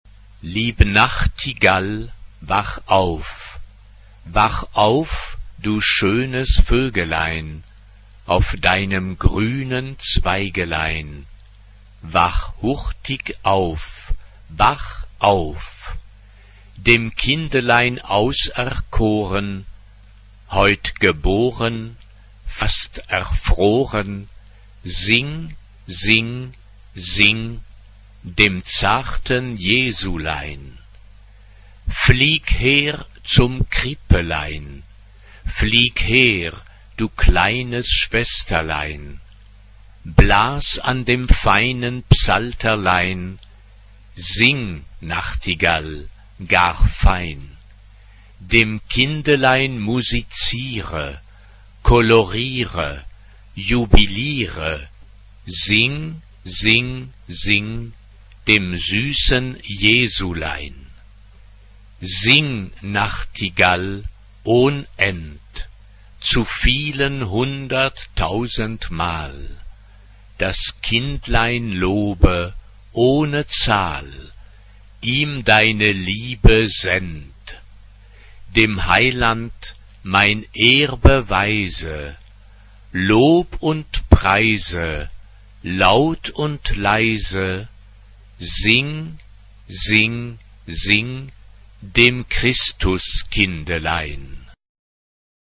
SSA (3 voix égales de femmes) ; Partition complète.
Sacré. noël. Traditionnel.
Tonalité : la bémol majeur